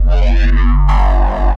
Bass 1 Shots (102).wav